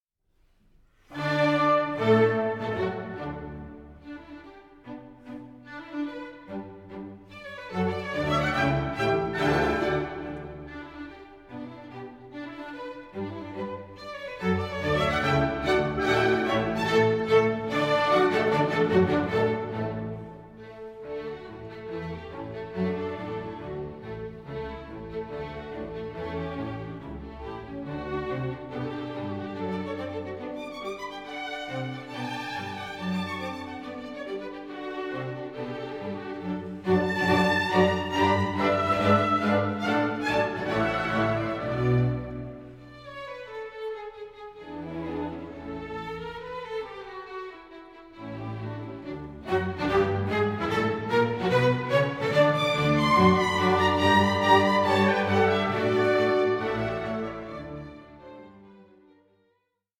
Concerto for Violin & Orchestra No. 2 in D Major